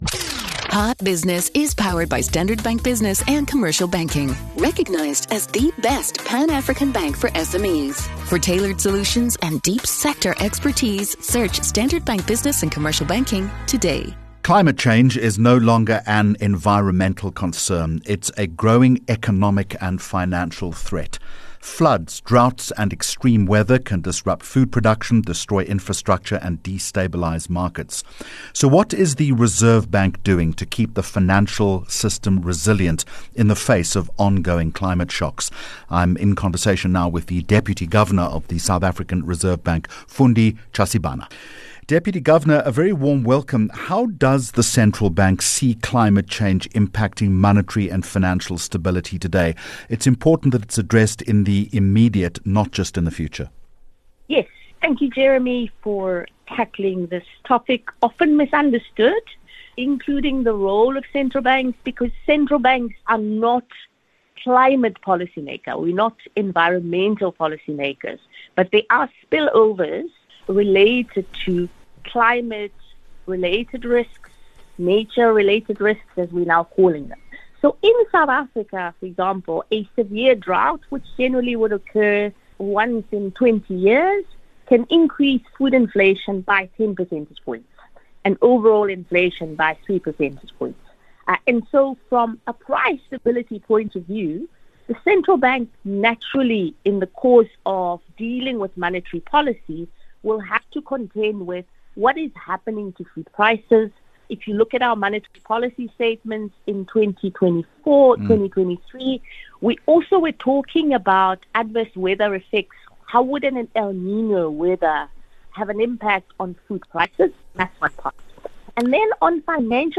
9 Jun Hot Business Interview -Fundi Tshazibana 09 June 2025